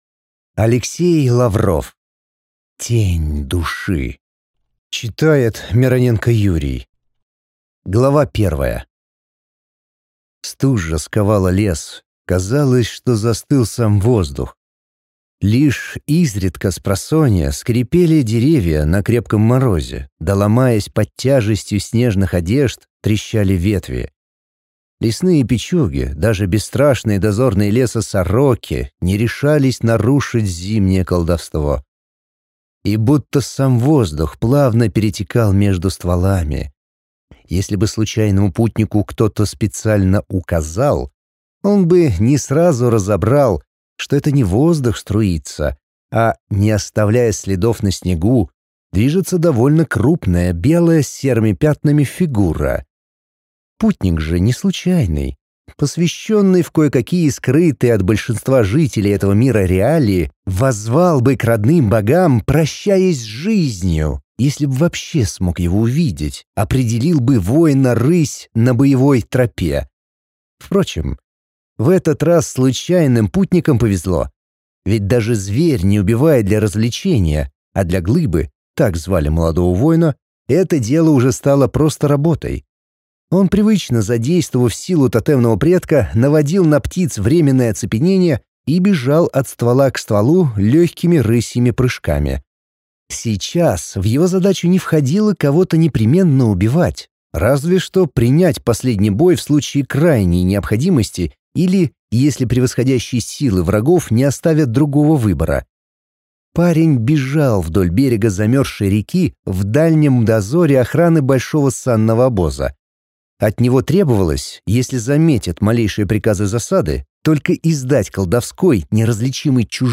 Аудиокнига Тень души | Библиотека аудиокниг